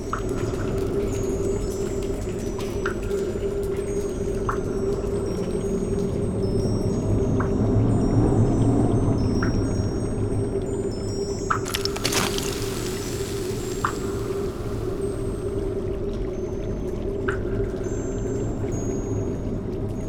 feat:ambiance
cave.wav